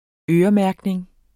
Udtale [ ˈøːʌˌmæɐ̯gneŋ ]